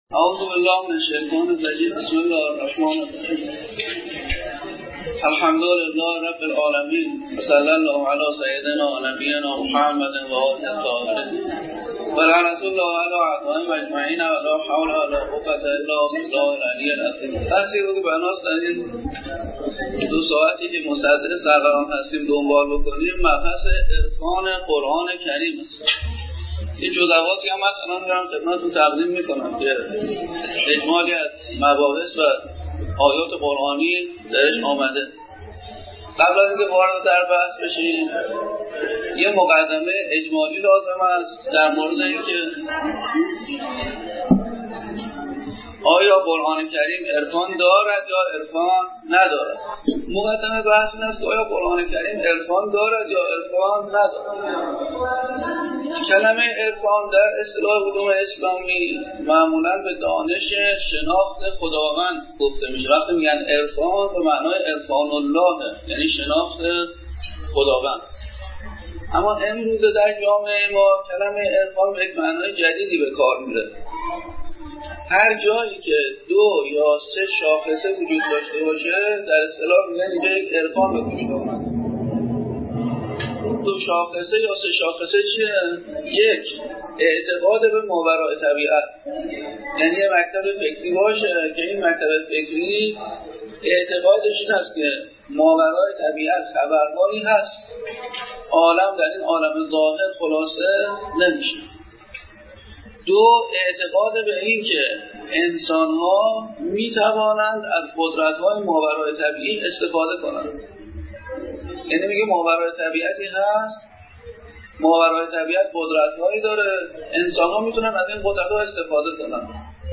در جمع معلمان دینی آموزش و پرورش این سخنرانی